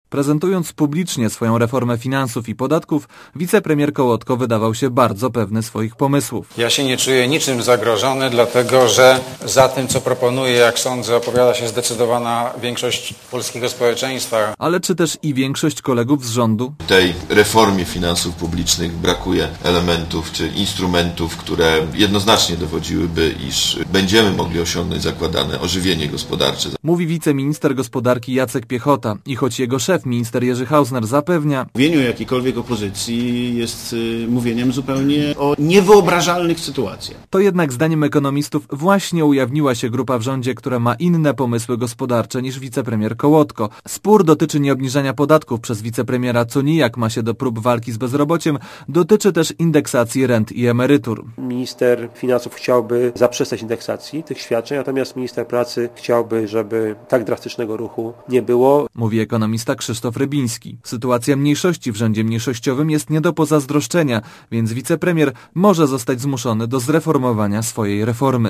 Sprawą zajął się reporter Radia Zet (535kb)